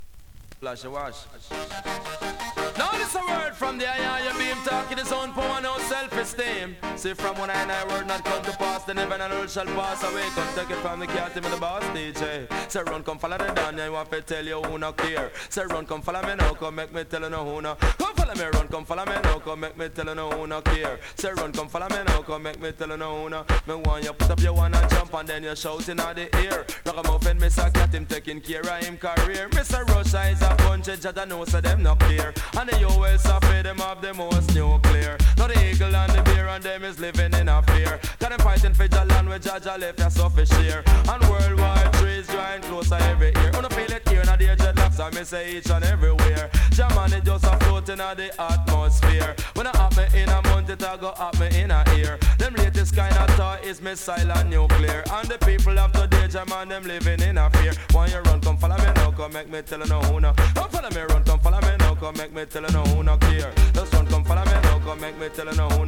DANCEHALL!!
スリキズ、ノイズかなり少なめの